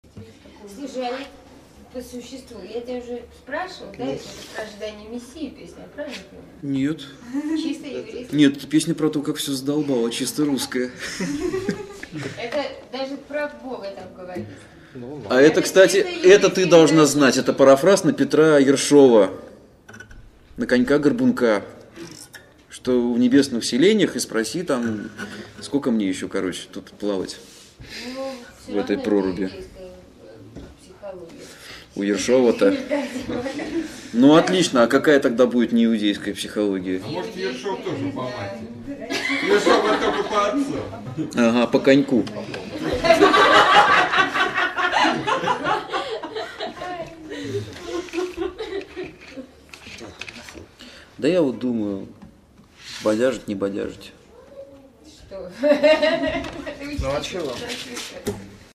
Квартирный концерт
Интермедия 11, теософическая: о мессианском характере предыдущей песни (940 Kb)